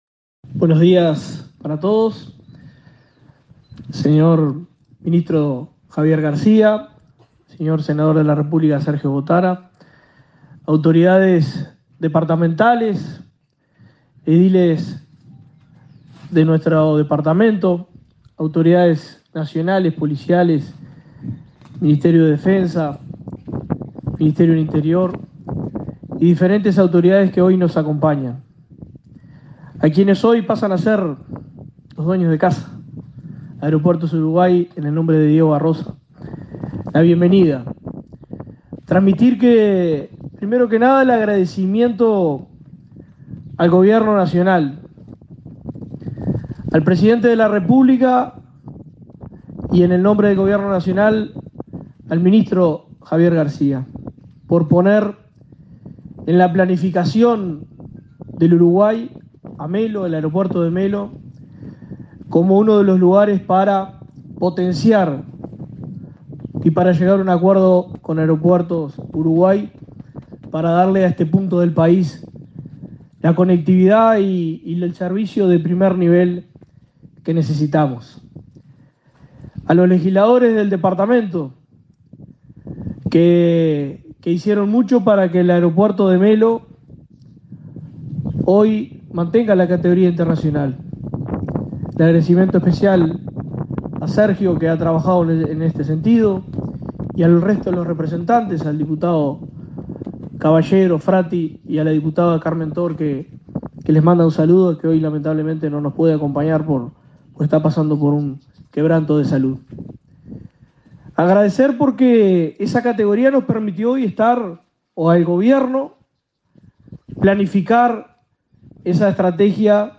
Palabras de autoridades en Cerro Largo
El intendente de Cerro Largo, José Yurramendi, y el ministro Javier García participaron este jueves 20 en el acto oficial de transferencia de la